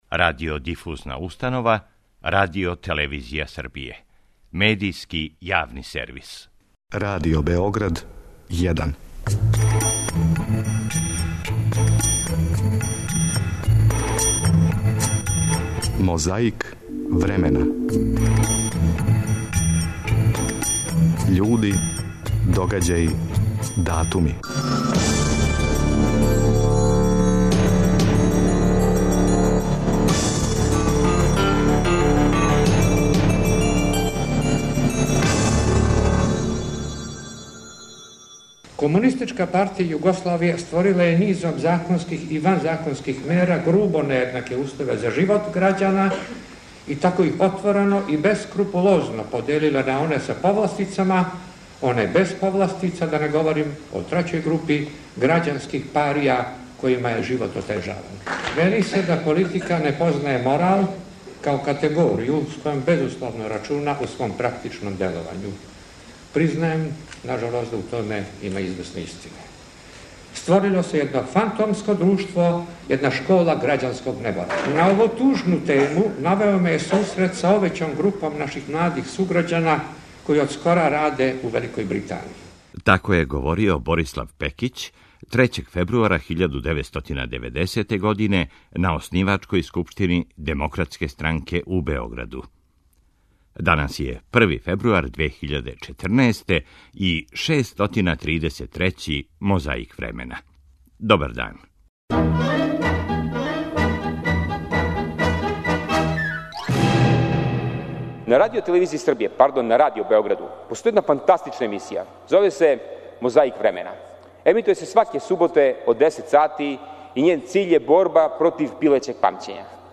У времену прошлом говорио је Борислав Пекић 3. фебруара 1990. године на оснивачкој скупштини Демократске странке у Београду.
Подсећамо на обраћање Зорана Ђинђића.
Подсећа на прошлост (културну, историјску, политичку, спортску и сваку другу) уз помоћ материјала из Тонског архива, Документације и библиотеке Радио Београда.